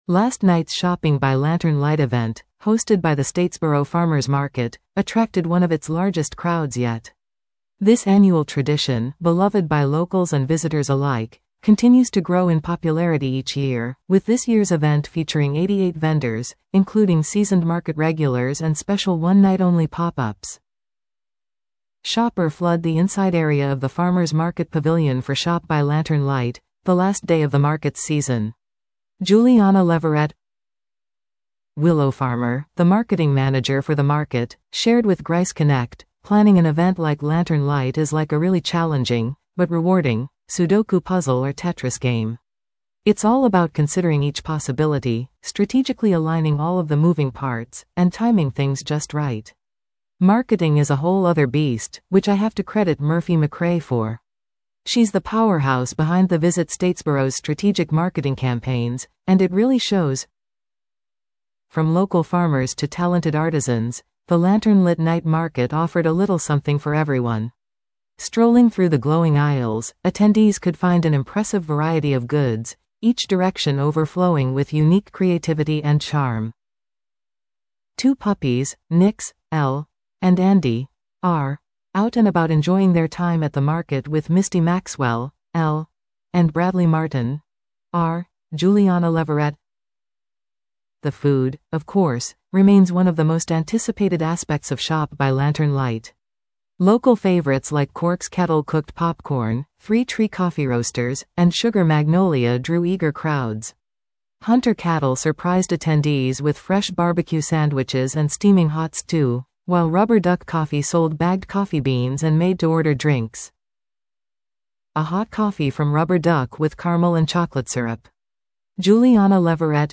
Gallery & Livestream | Statesboro Farmers Market dazzles crowds with annual Shopping by Lantern Light